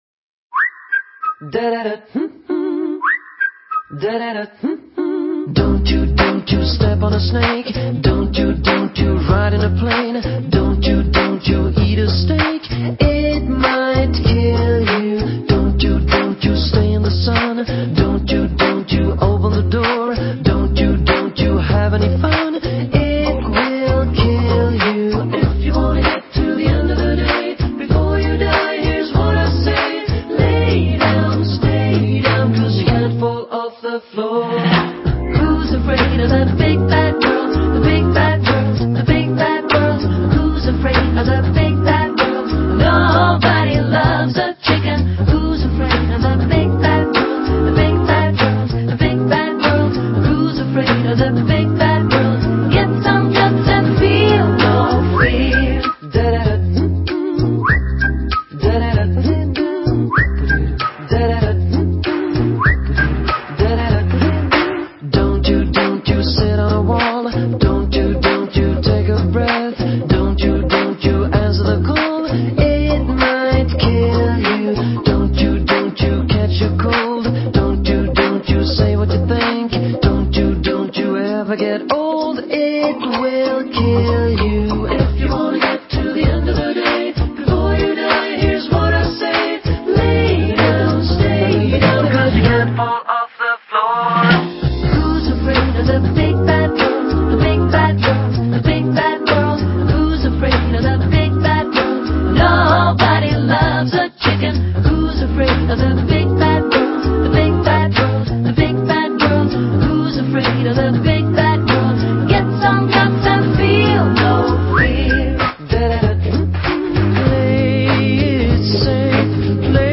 Vocal jazz